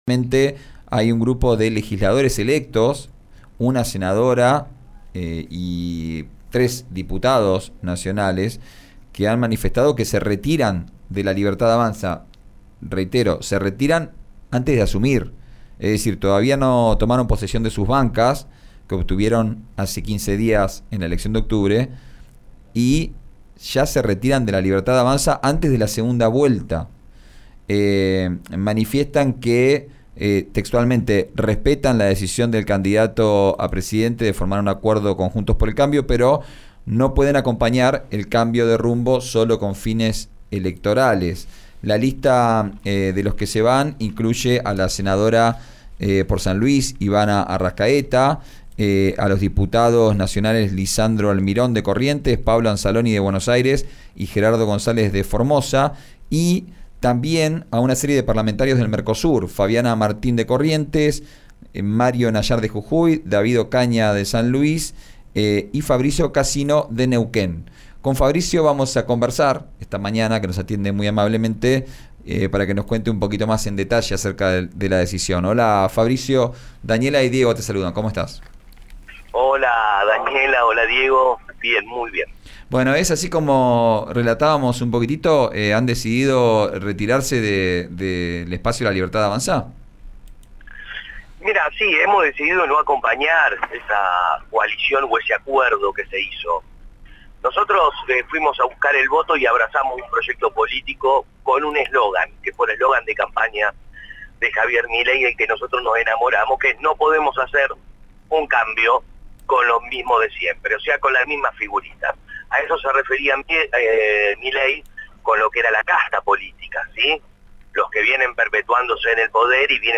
Esta vez fue un legislador de La Libertad Avanza, electo por la provincia de Neuquén, el que se refirió al tema en medio de la campaña rumbo al balotaje del 19 de noviembre y aseguró que, en su momento, creyó firmemente en la presunta relación entre el libertario y la profecía.